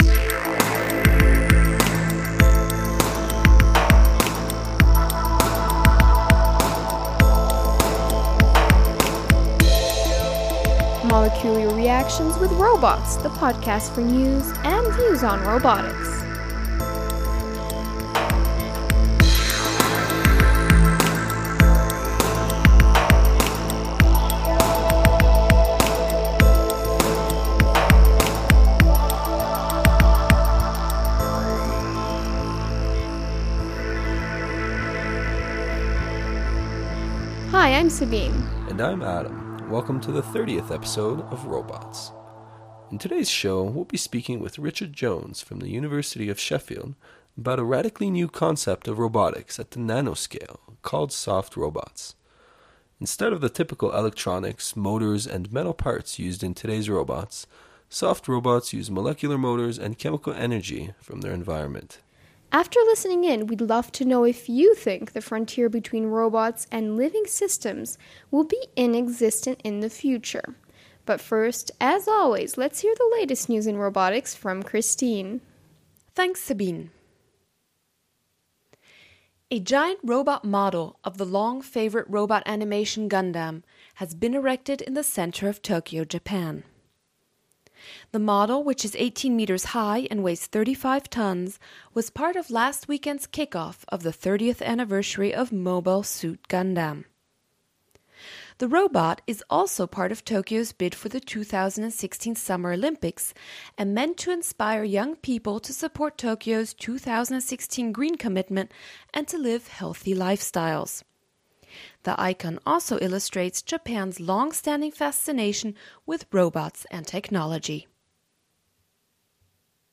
View and post comments on this episode in the forum tags: bio-inspired , podcast Podcast team The ROBOTS Podcast brings you the latest news and views in robotics through its bi-weekly interviews with leaders in the field.